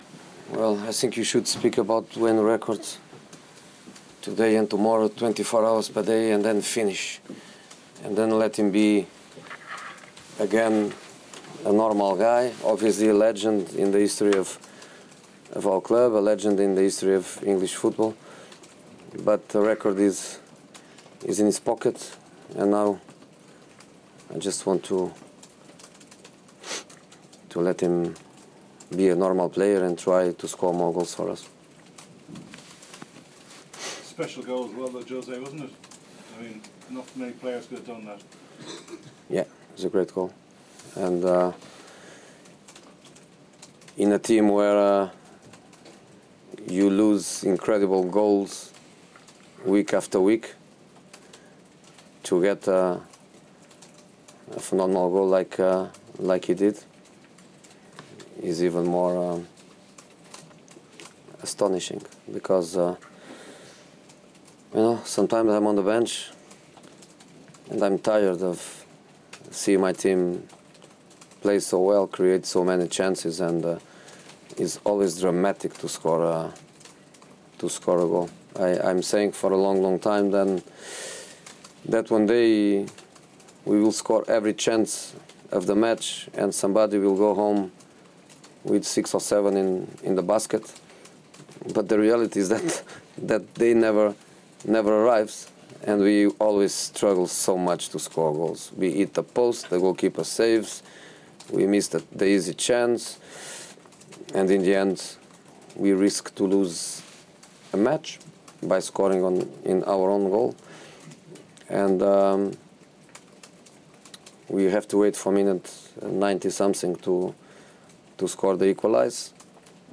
Manchester United manager Jose Mourinho speaks following his side's 1-1 draw at Stoke, and talks about Wayne Rooney's 250th record breaking goal for the club.